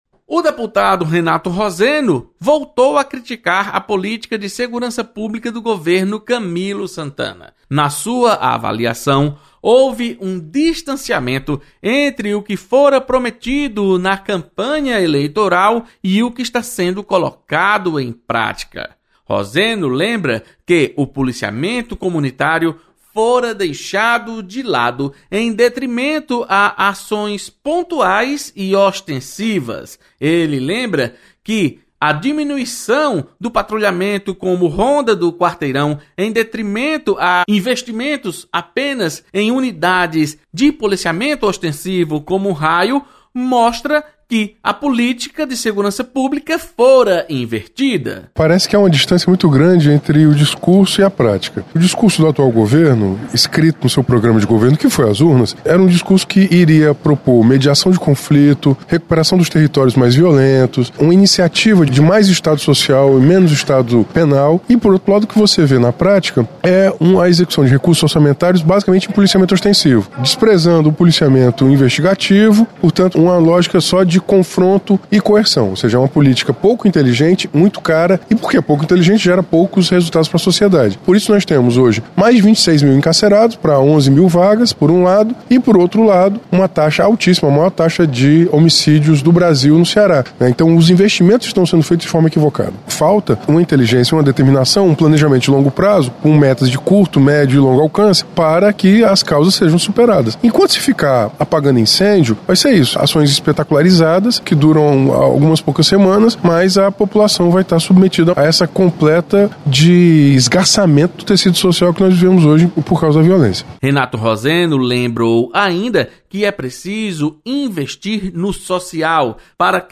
Deputado Renato Roseno critica política de segurança pública. Repórter